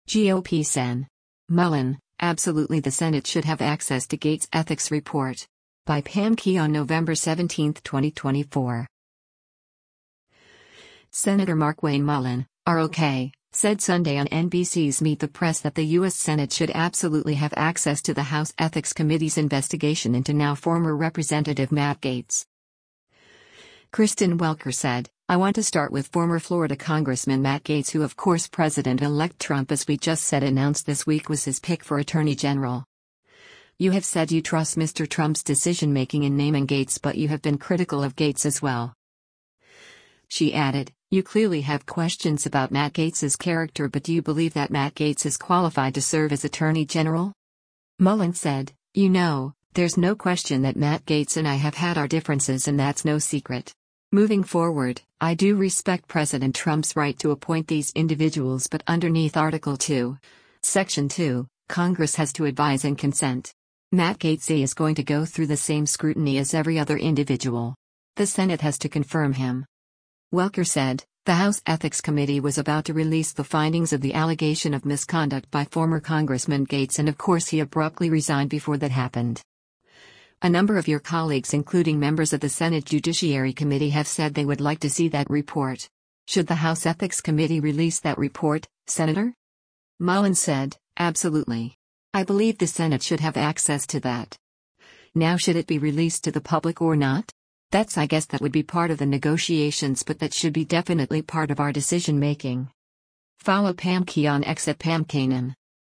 Senator Markwayne Mullin (R-OK) said Sunday on NBC’s “Meet the Press” that the U.S. Senate should “absolutely” have access to the House Ethics Committee’s investigation into now-former Rep. Matt Gaetz.